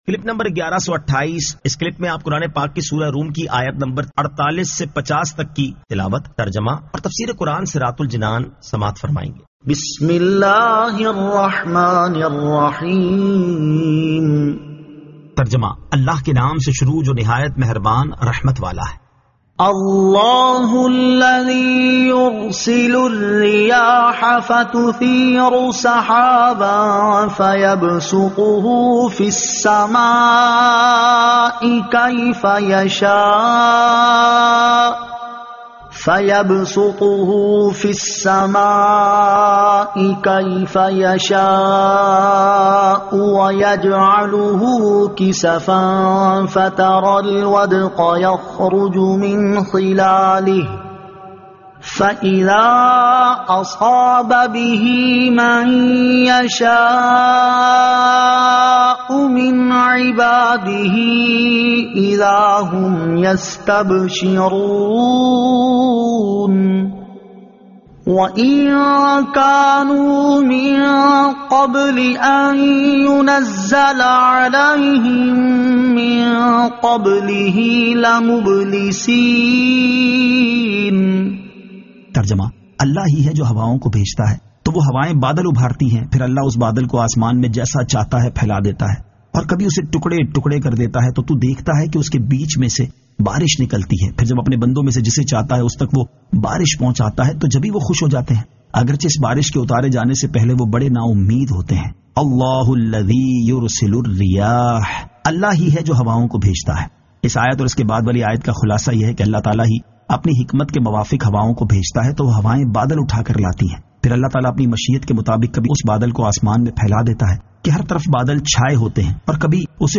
Surah Ar-Rum 48 To 50 Tilawat , Tarjama , Tafseer